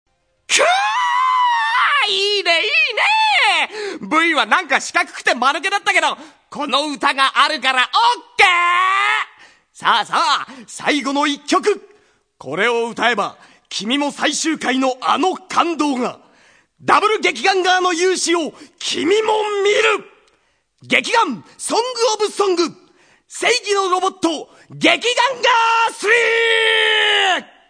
Seki Tomokazu-sanGai Daigohji's seiyuu (or voice actor) is none other than Seki Tomokazu, my third favorite seiyuu.
These sounds are from the Gekiganger Karaoke section of the soundtrack where Gai introduces each song for the karaoke section.
Gai's Hotblooded Talk #4 - Gai introduces Seigi no Robotto, Gekiganger 3!